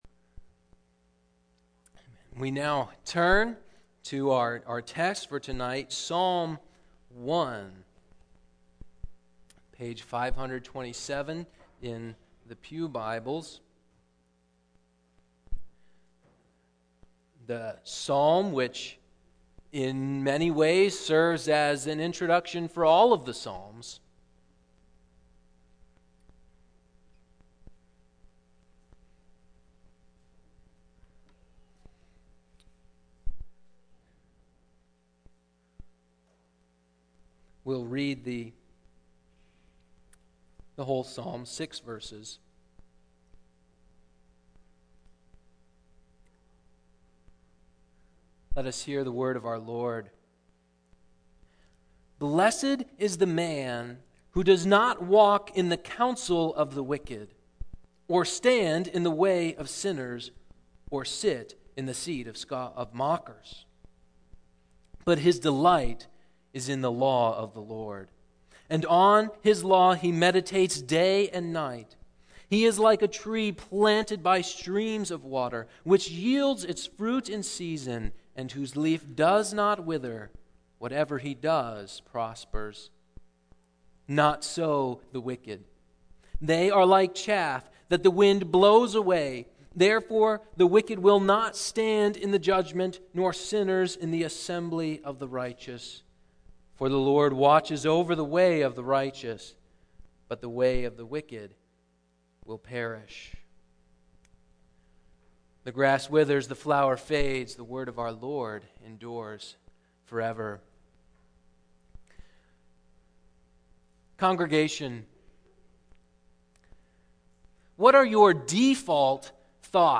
Single Sermons Passage